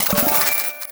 GetCoin.wav